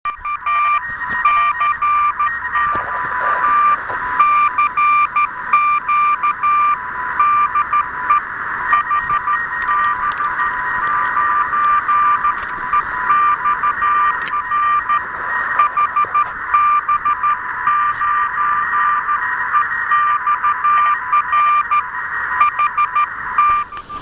Прекрасно прошёл "Осенний полевой день".
Ещё немного аудио, в том числе запись шума ветра, романтика всё- таки, как никак!